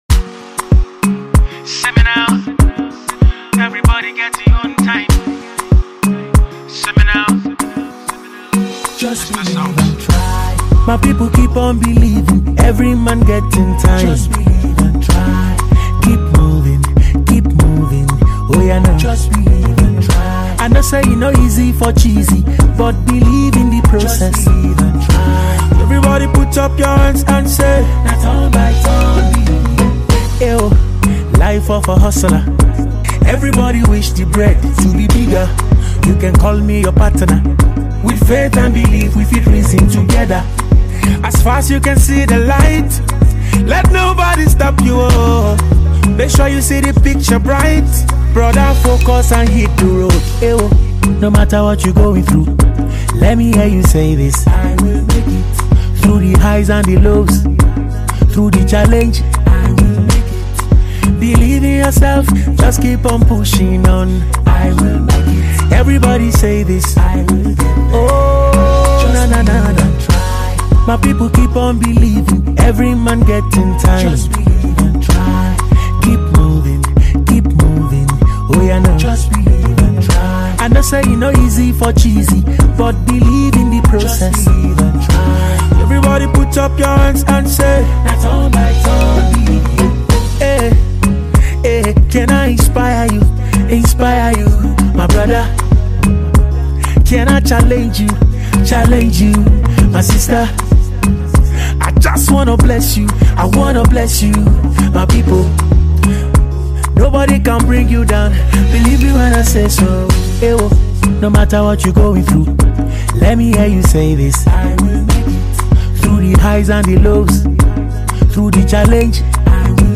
motivational single
Nigerian talented singer